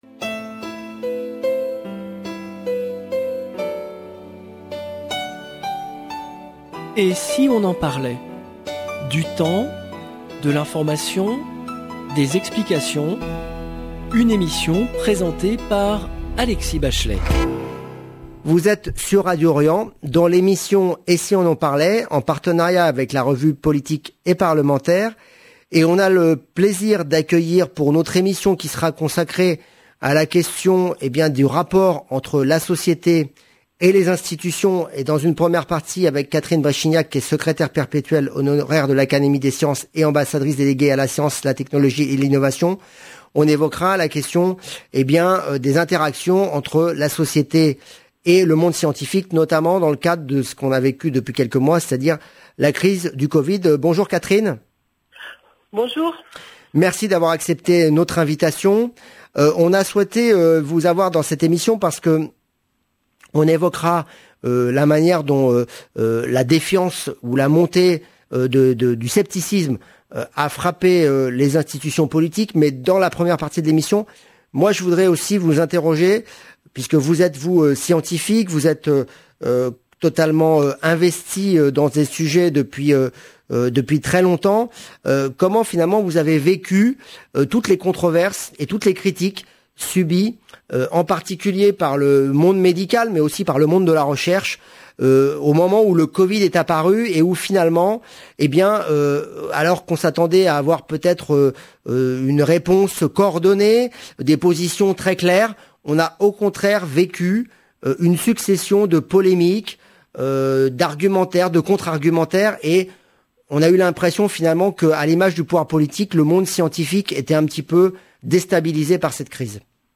Emission diffusée le jeudi 11 novembre 2021